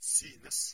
Ääntäminen
IPA: [pɑ̃.se]